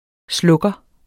Udtale [ ˈslɔgʌ ]